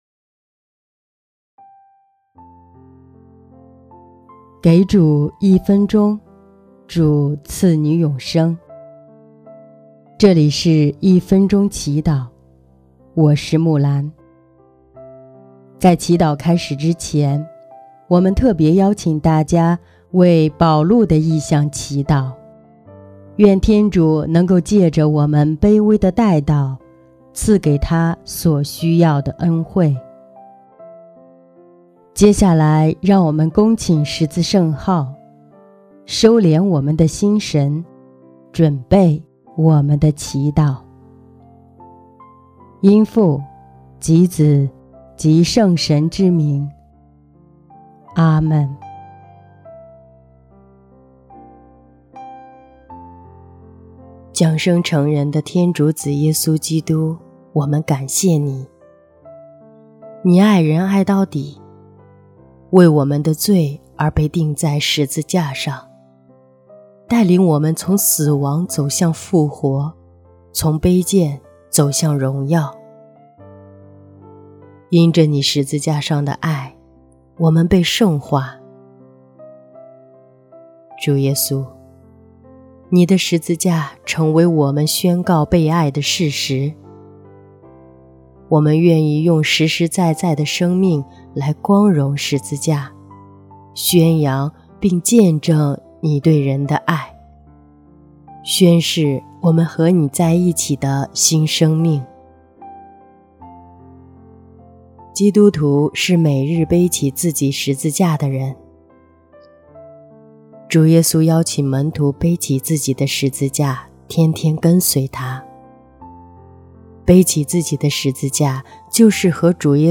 音乐：第二届华语大赛优秀奖《十字架》